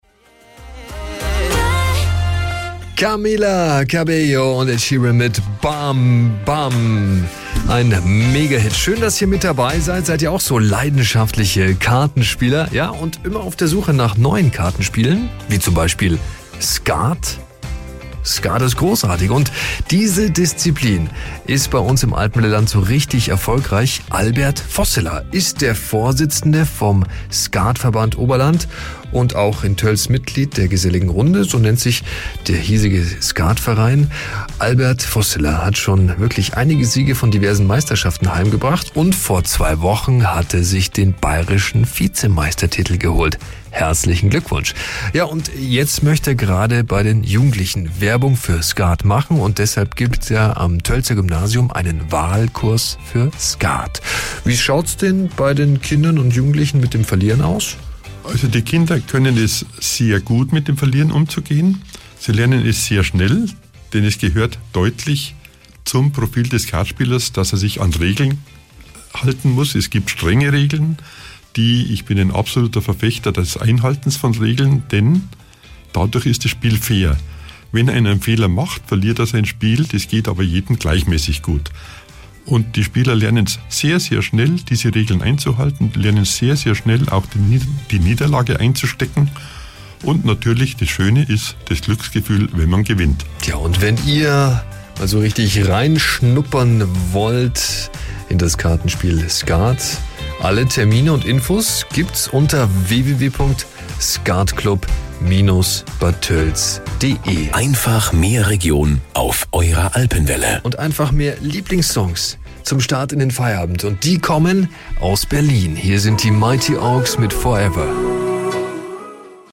Morgens um 6.10 Uhr ging es los mit den jeweils knapp 2-minütigen Blöcken, die aus dem nahezu einstündigen Interview (siehe auch unten!) zusammengeschnitten wurden.